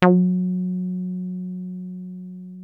303 F#3 2.wav